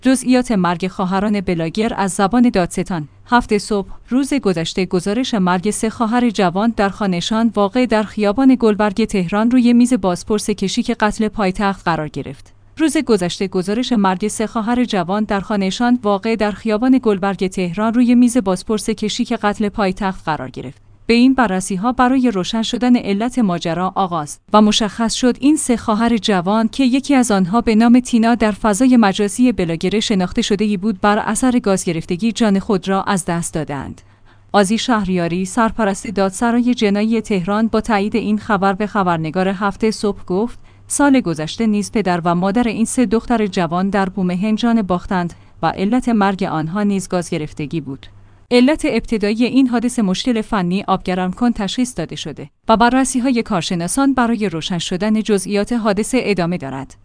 جزئیات مرگ خواهران بلاگر از زبان دادستان